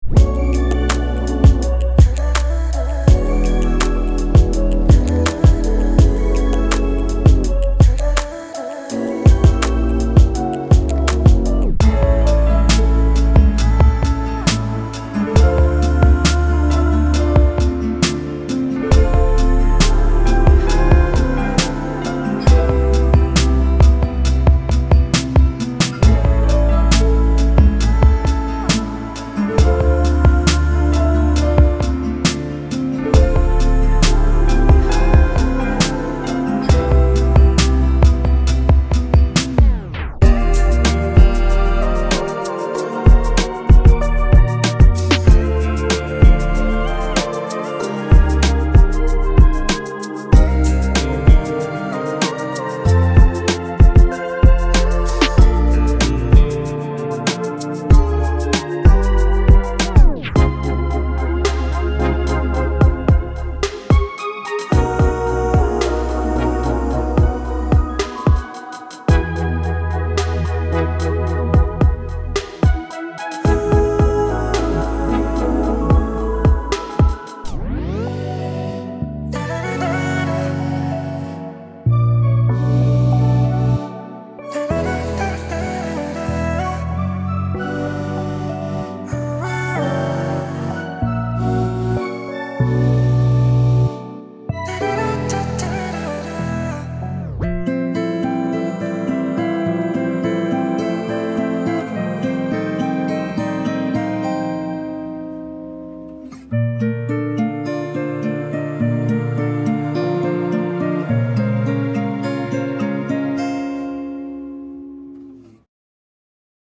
Rnb
dreamy textures with emotional depth